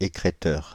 Ääntäminen
Ääntäminen France (Île-de-France): IPA: /e.kʁɛ.tœʁ/ Haettu sana löytyi näillä lähdekielillä: ranska Käännös Substantiivit 1. suppressor Määritelmät Substantiivit Dispositif destiné à supprimer les surtensions , par exemple celles dues à la foudre ou à des décharges électrostatiques , ou à limiter l' excursion d'un signal .